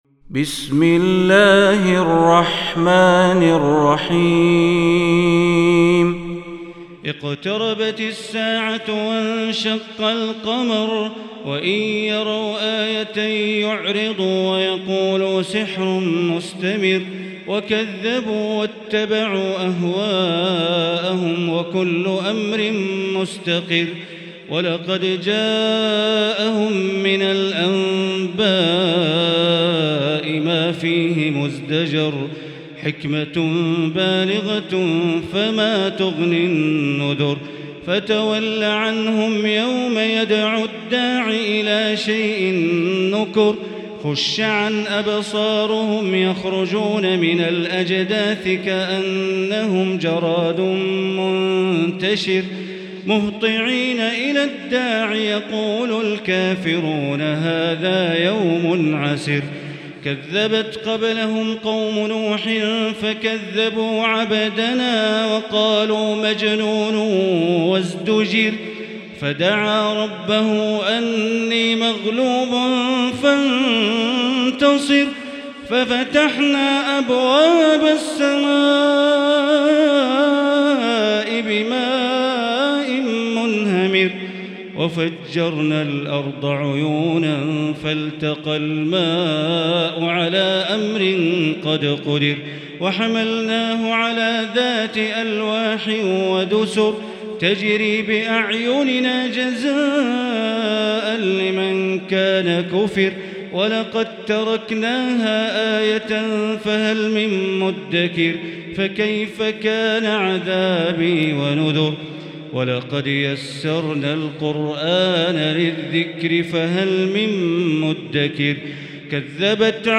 المكان: المسجد الحرام الشيخ: معالي الشيخ أ.د. بندر بليلة معالي الشيخ أ.د. بندر بليلة القمر